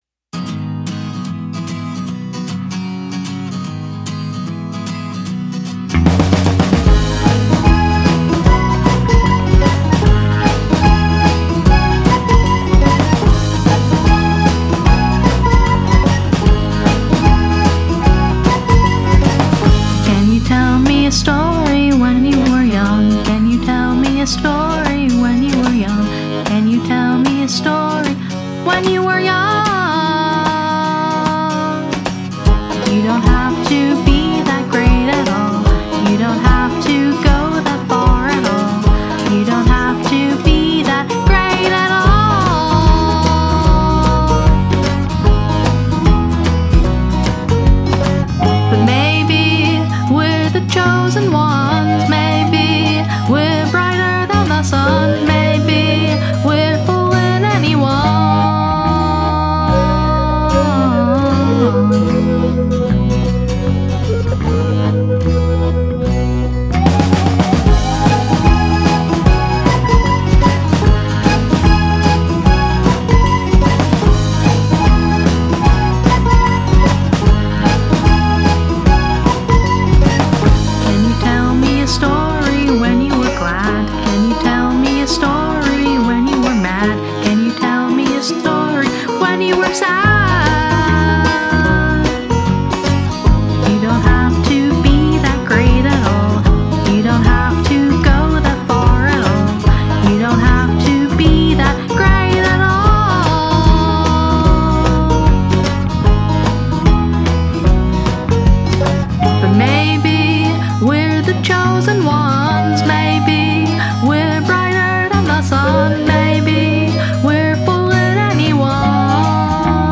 Created 2024-04-20 02:23:08 Alternative Rock 0 ratings